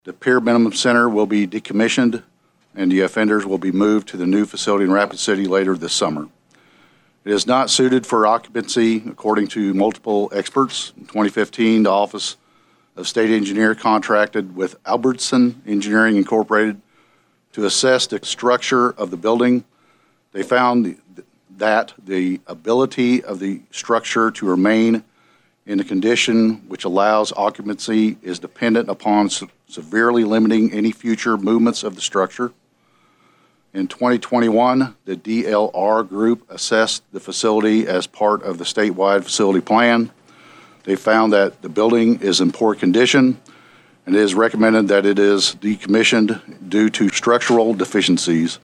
Nick Lamb, state Corrections Secretary, said House Bill 1046 would allocate $683,000 to demolish the Pierre Minimum Center, which has been the plan since the approval of construction of a new women’s prison at Rapid City.